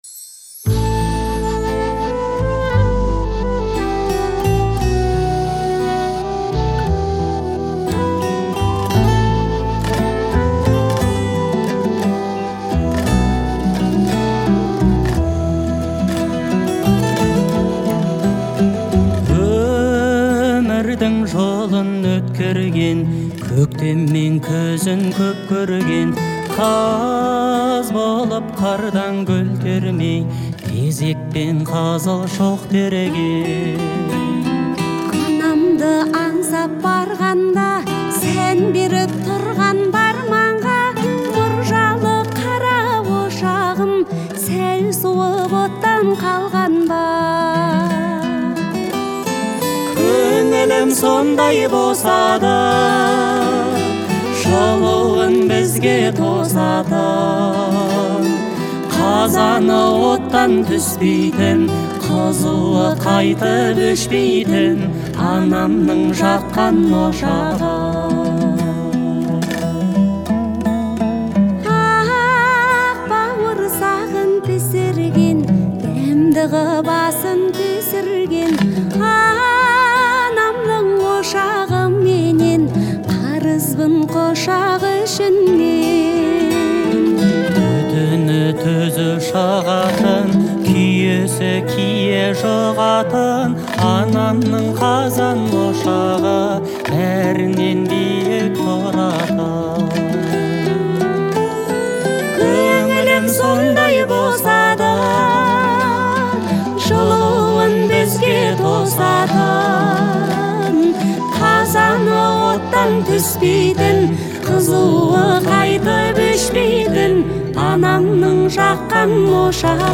это трогательная песня в жанре казахской поп-музыки